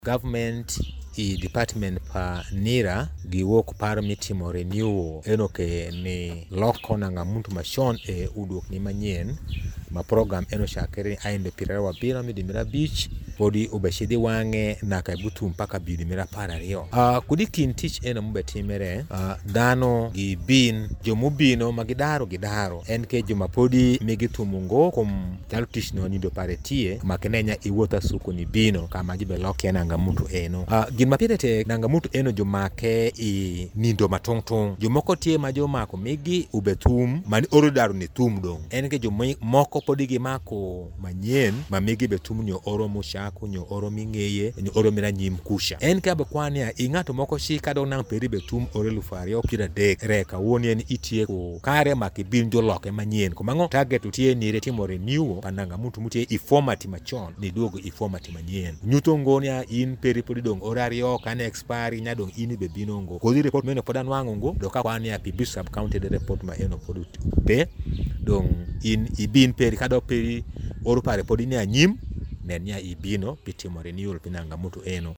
Denis Oyewa, the LC3 Chairperson of Biiso Sub-county, made the appeal during an interview at Biiso Town Council Community Hall.